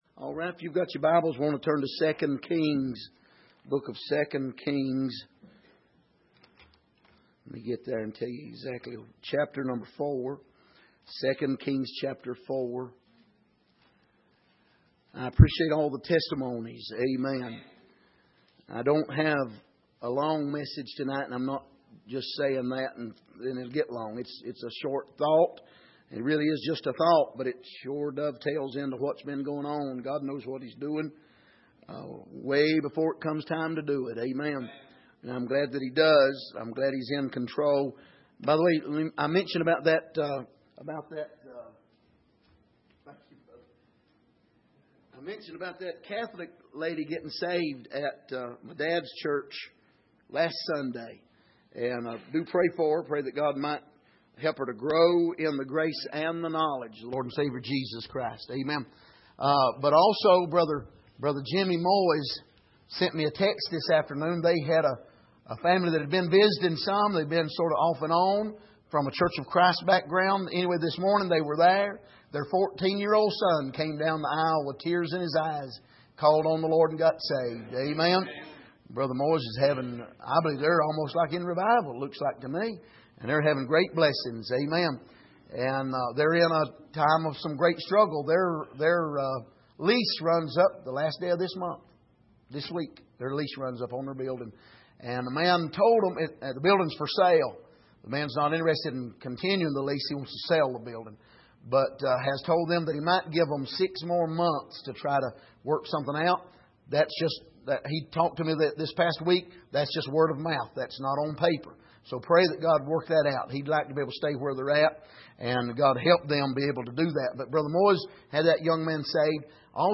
Passage: 2 Kings 4:1-7 Service: Sunday Evening